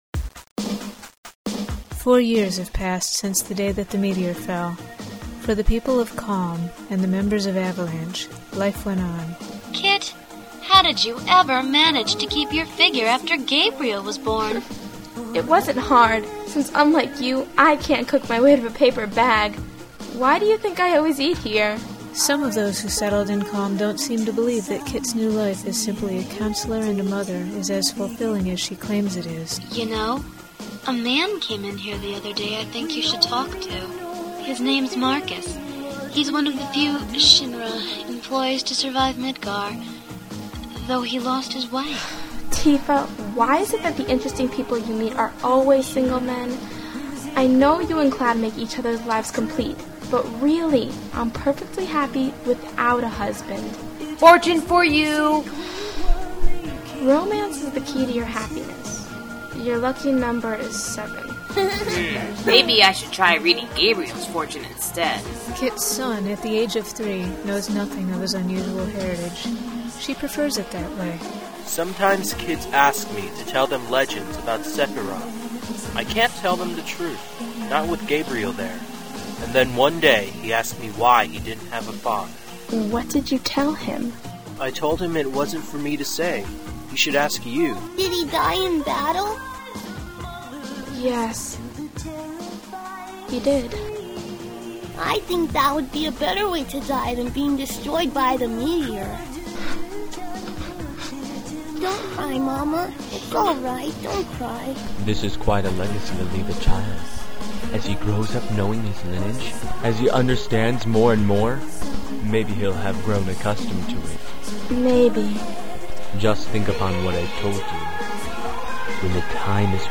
An audio trailer for a fanfic?